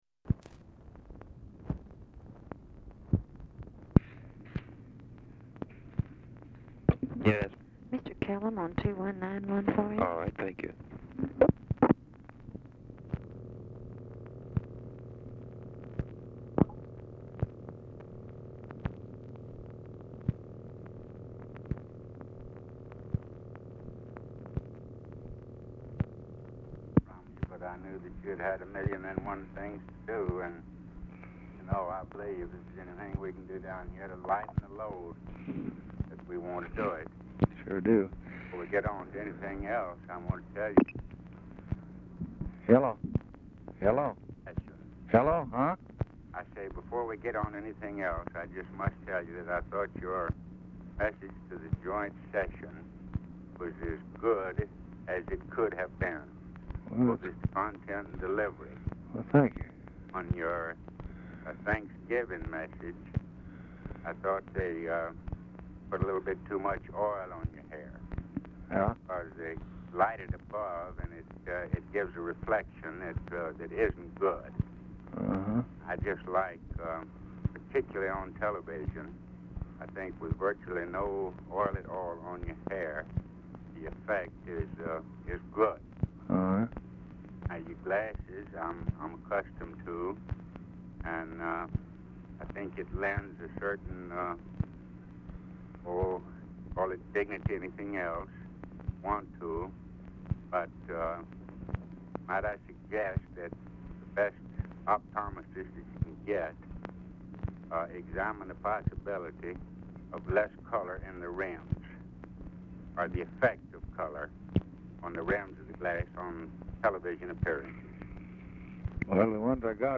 RECORDING STARTS AFTER CONVERSATION HAS BEGUN
RECORDING IS BRIEFLY INTERRUPTED BY ANOTHER CALL
Format Dictation belt
Specific Item Type Telephone conversation